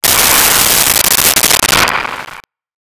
Breath 01
Breath 01.wav